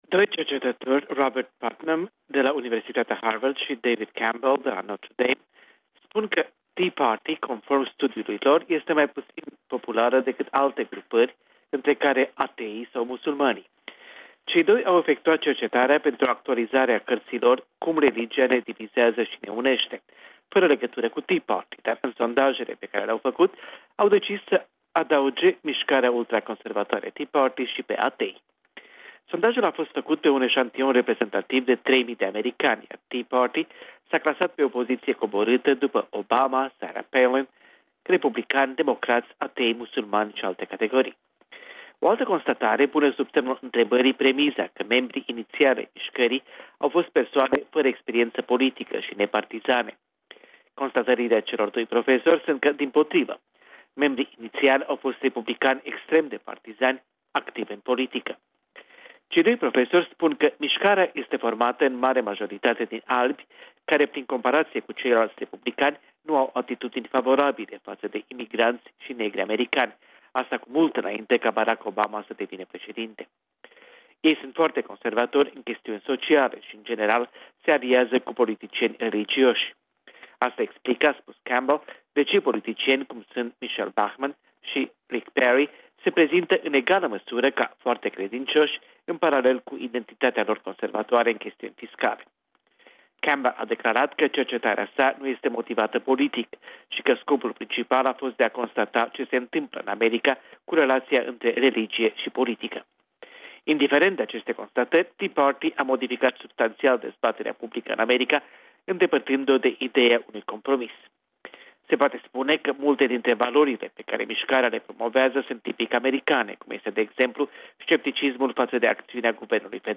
Corespondenţa zilei de la Washington